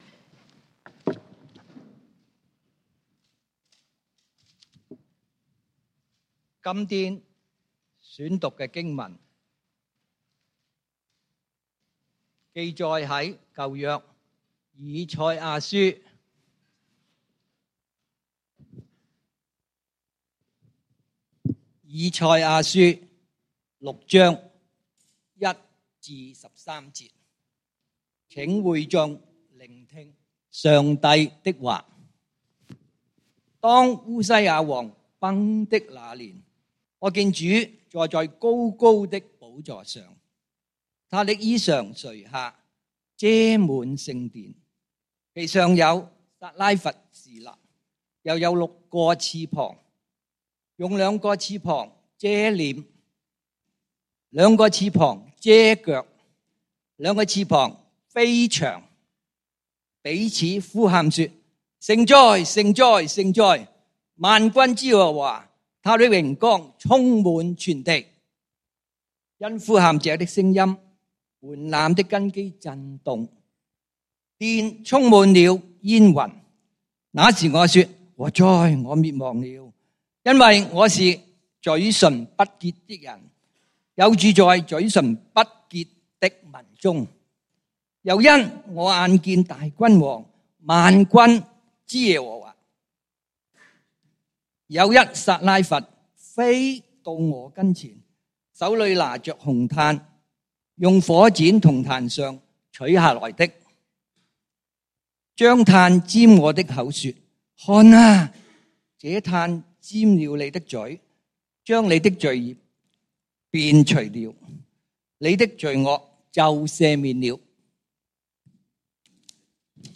5/26/2024 講道經文：以賽亞書 Isaiah 6:1-13 本週箴言：以賽亞書 Isaiah 6:8 我又聽見主的聲音說：「我可以差遣誰呢？